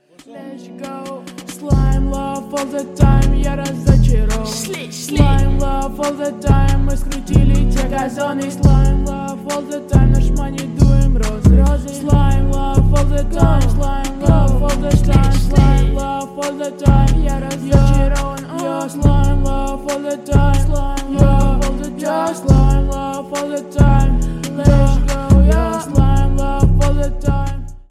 Жанр: Иностранный рэп и хип-хоп